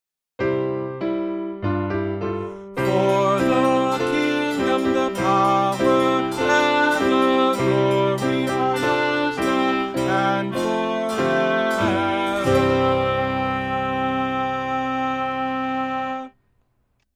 Voice | Downloadable